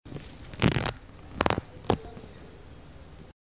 Despegando un velcro
Grabación sonora del sonido producido por un velcro al ser despegado, separado, de su otra parte.
Sonidos: Acciones humanas